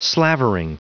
Prononciation du mot slavering en anglais (fichier audio)
Prononciation du mot : slavering